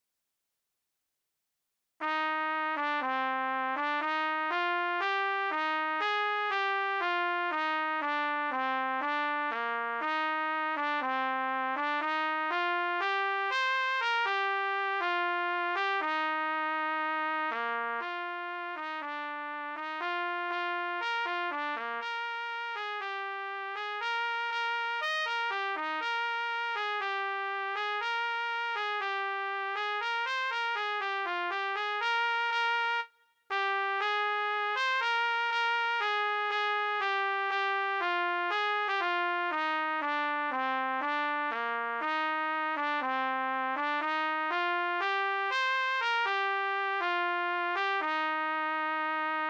A=Melody-for more experienced players